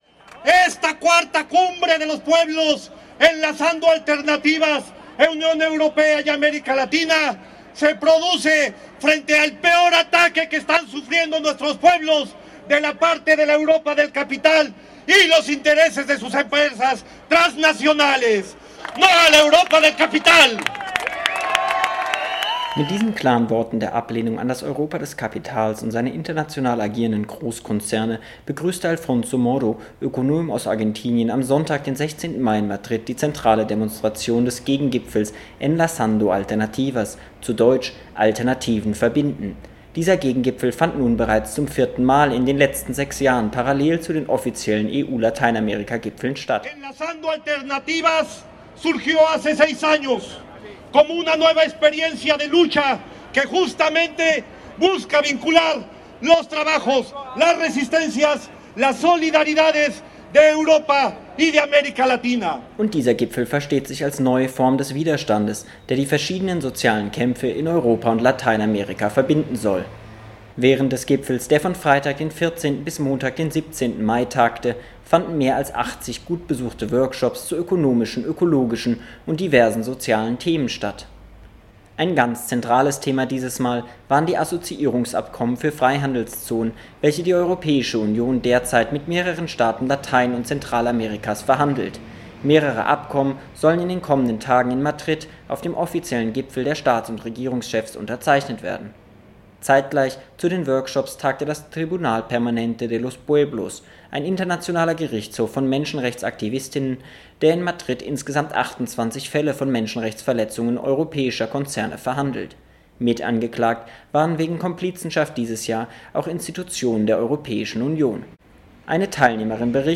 Bericht vom Enlazando Alternativas in Madrid - Programm, Demo, Überblick